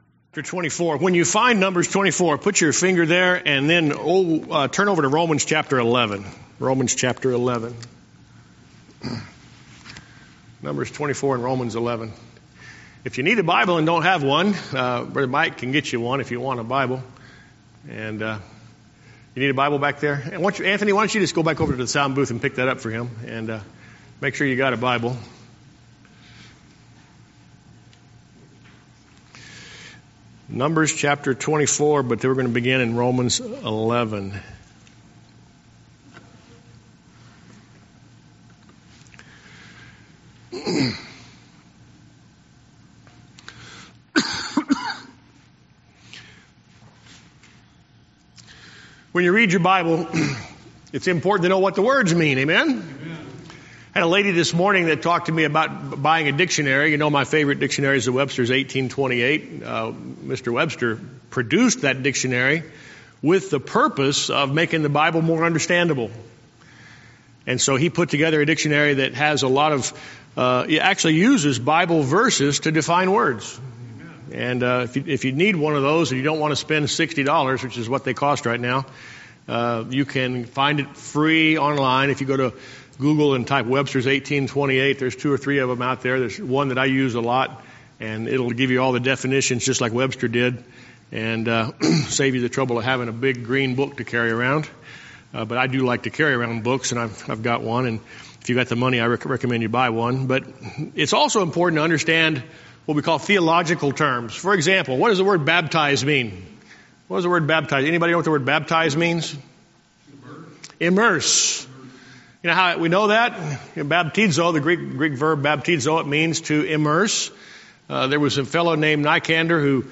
Sermon Recordings
Sermons